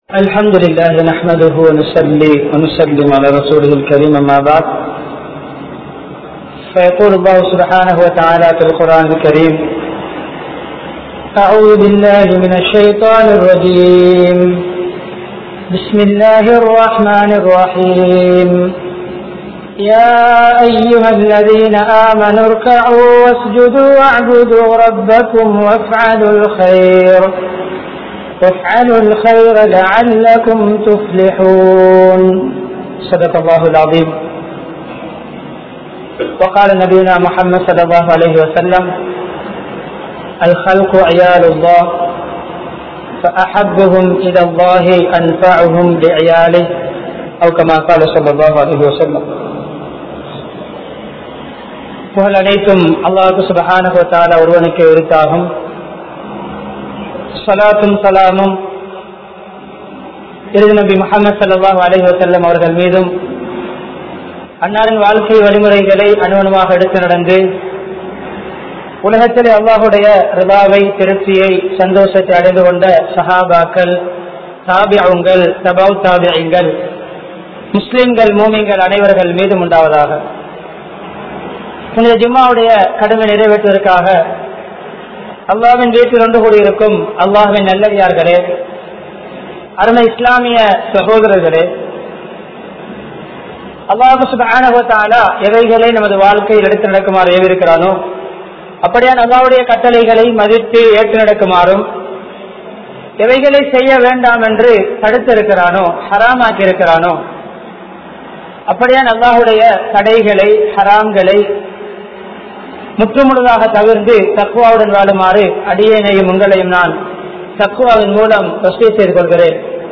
Samookathitku Naam Aatra Vendiya Panikal(சமூகத்திற்கு நாம் ஆற்ற வேண்டிய பணிகள்) | Audio Bayans | All Ceylon Muslim Youth Community | Addalaichenai
Gothatuwa, Jumua Masjidh